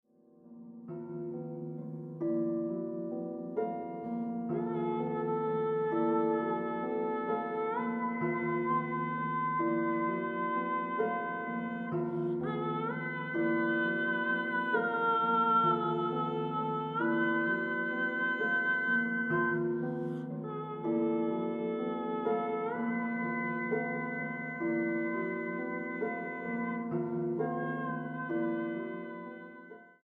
mezzosoprano
pianista.
Grabado en la Sala Nezahualcóyotl